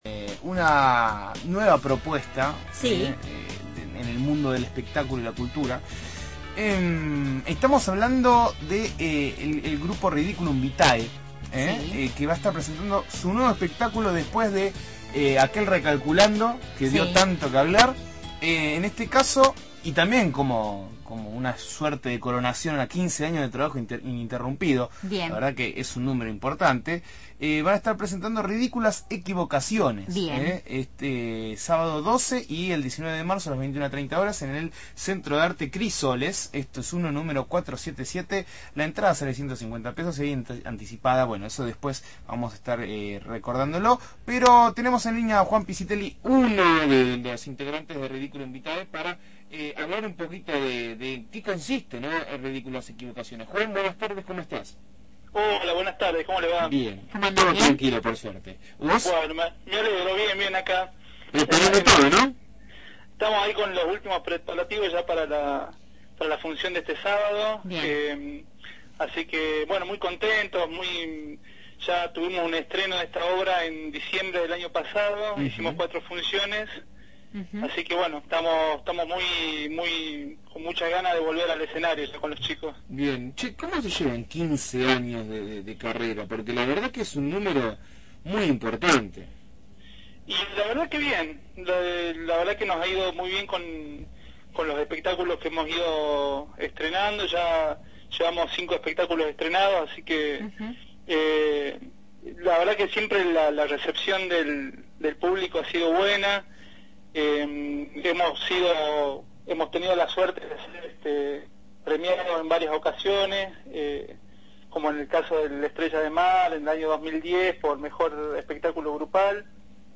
integrante del grupo humorístico «Ridículum Vitae», dialogó con